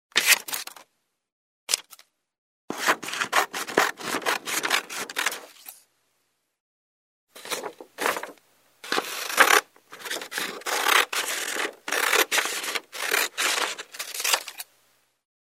Звуки режущих ножниц
Звук ножниц разрезающих бумагу или картон